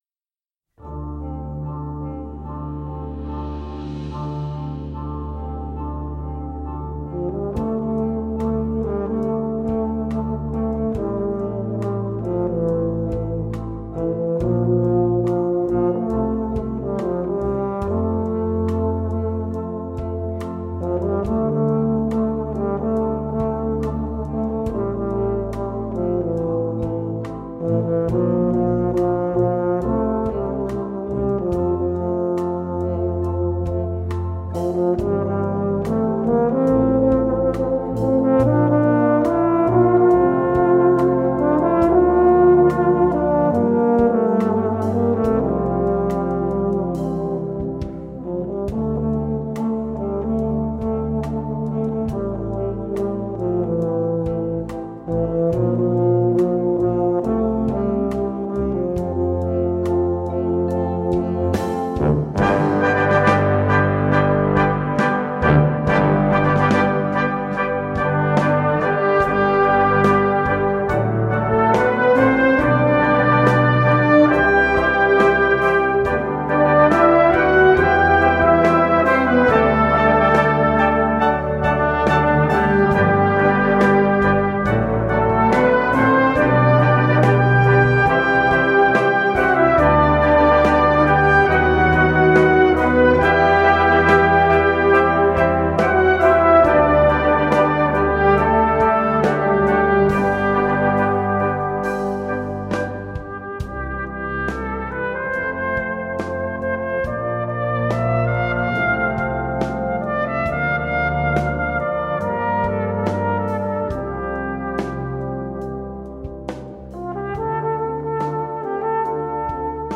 Besetzung: Vocal Solo & Ten Piece